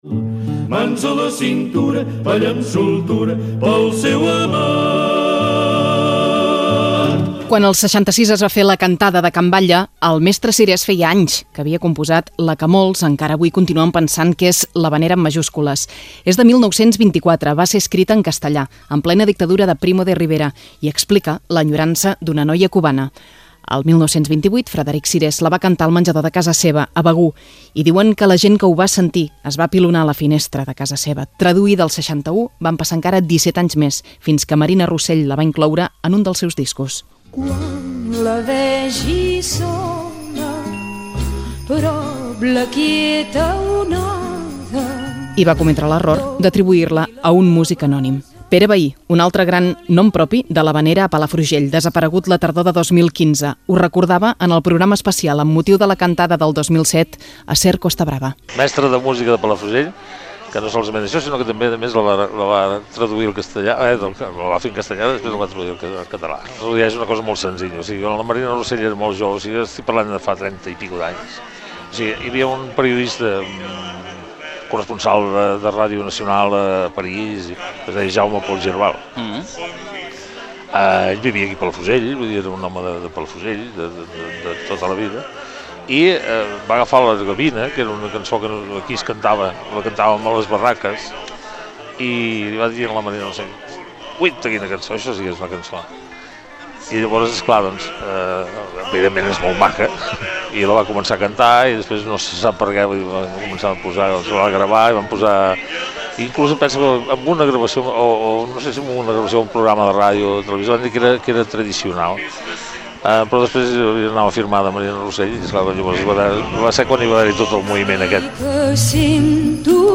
Reportatge 50 anys de la cantada d'havaneres a Calella - Ràdio Girona, 2016